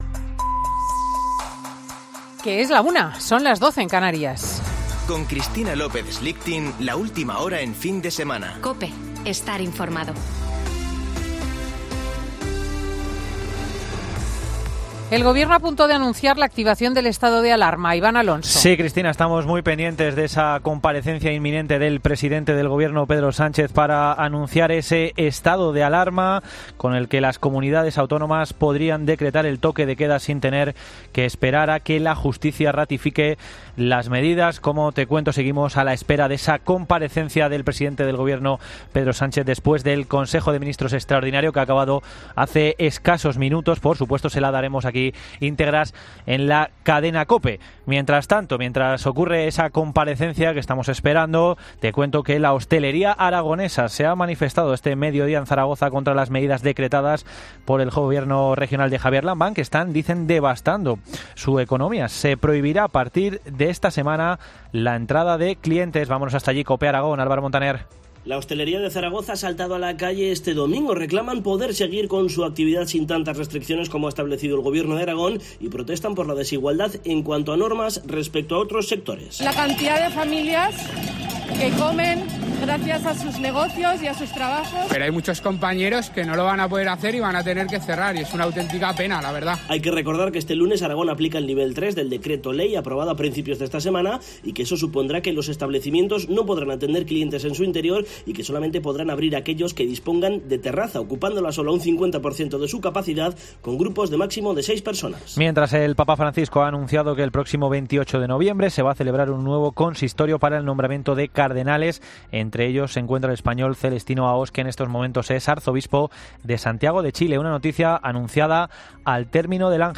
Boletín de noticias de COPE del 25 de octubre de 2020 a las 13.00 horas